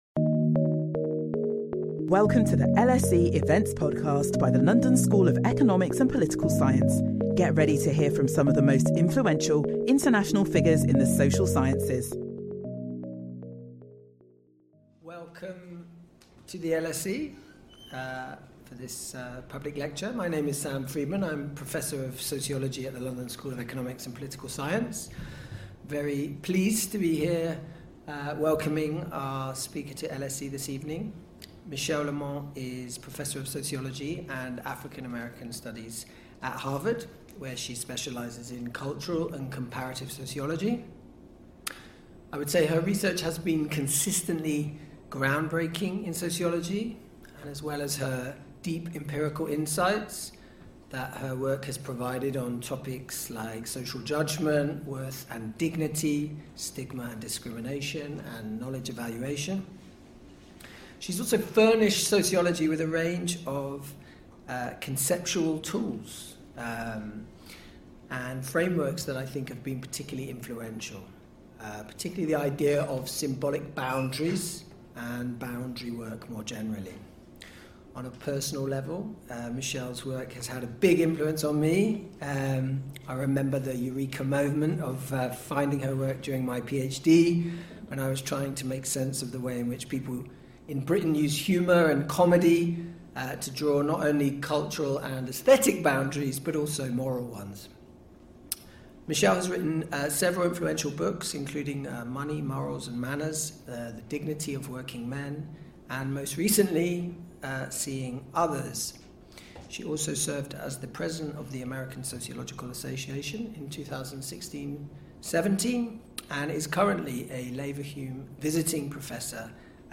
Join us for this lecture in which Michèle Lamont will discuss her book Seeing Others: How Recognition Works and How it Can Heal a Divided World.